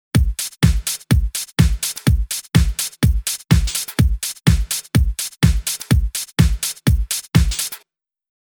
ディープハウス（BPM125）
派手すぎないサウンドで叙情的な雰囲気があるディープハウス。
ドラムパターン自体はほかと大きく変わりませんが、裏拍のオープンハイハットの音色がおとなしめな印象です。
あとはキックも硬質で、ドンドンというよりかはドッドッという感じですね。